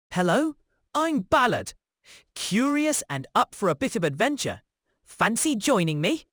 NavTalk 提供多种高质量的语音合成风格，您可以通过 voice 参数自由选择数字人音色：
温暖叙事型男声